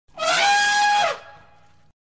Turkey Gobble
Turkey Gobble is a free animals sound effect available for download in MP3 format.
500_turkey_gobble.mp3